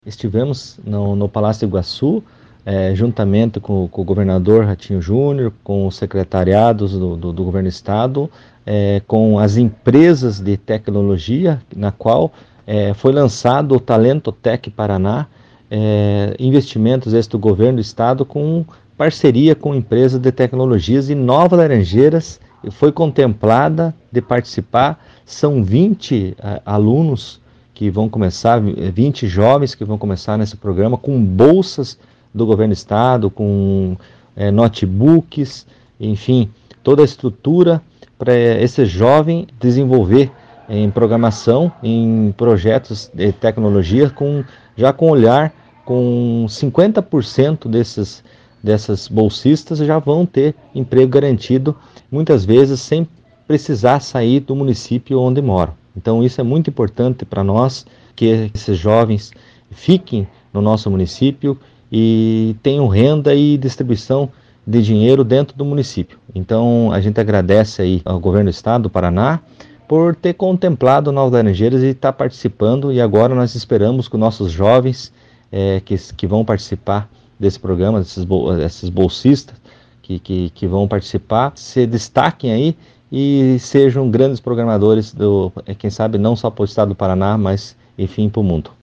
SONORA – FABIO DOS SANTOS